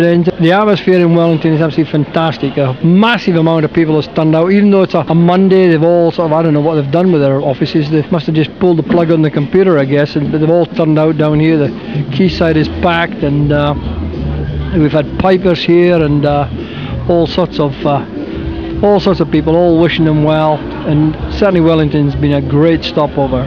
The following clips were recorded during the race.